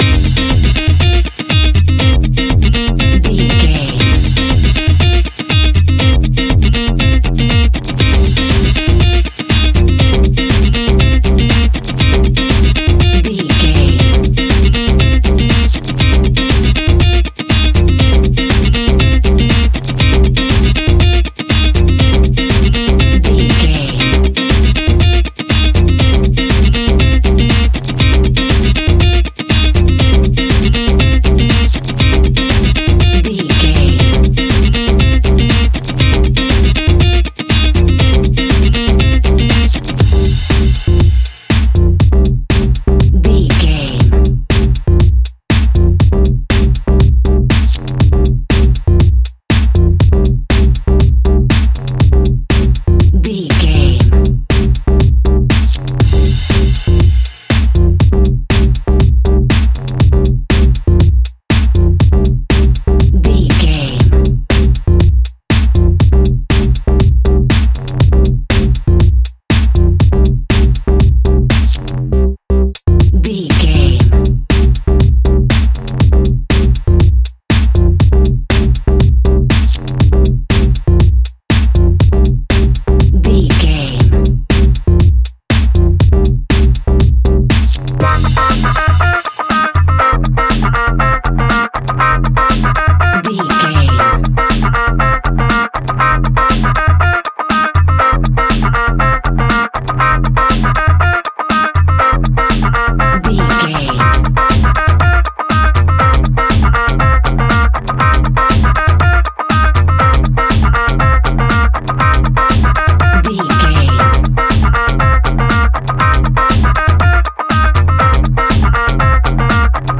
Aeolian/Minor
driving
energetic
repetitive
electric guitar
bass guitar
drums
synthesiser
drum machine
piano
funky house
electronic funk
upbeat
synth leads
Synth Pads
synth bass